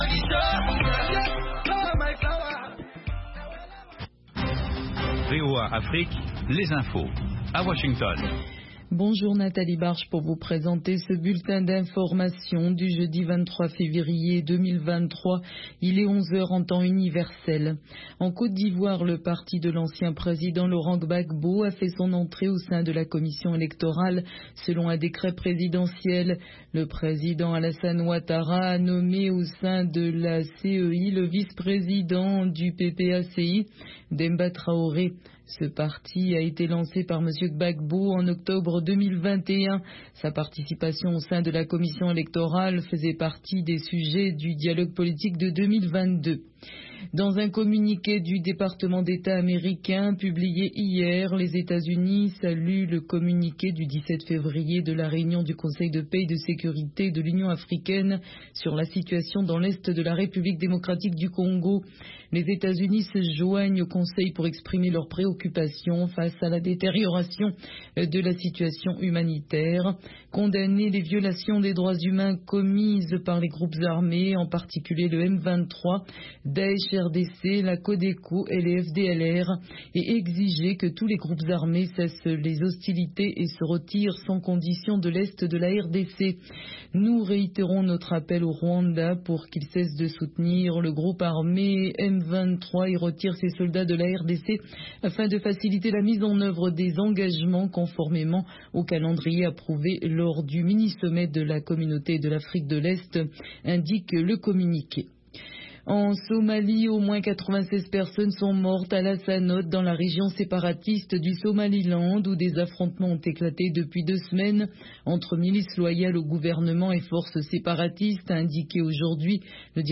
10 Minute Newscast